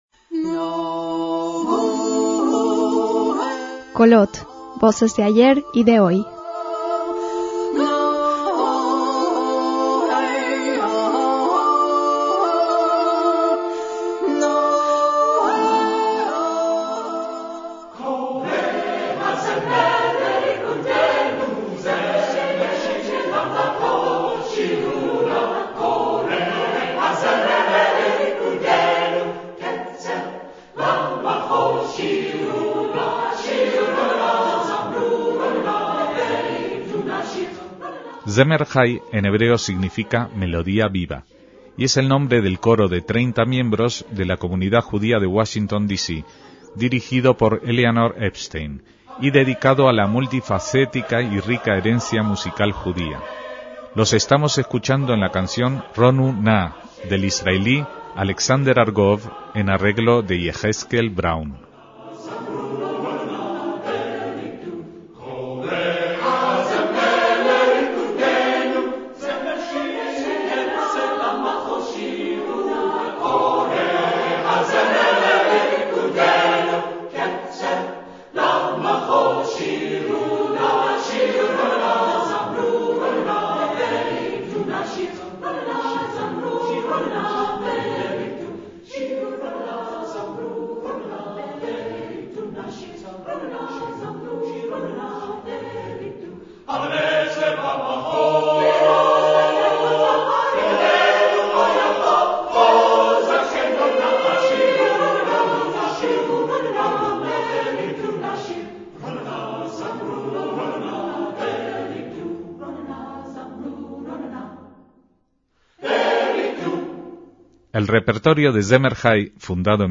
coro mixto
conjuntos vocales judíos